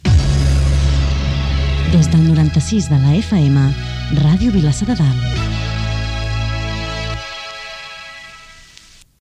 Indicatiu de l'emissora a la freqüència de 96 MHz
FM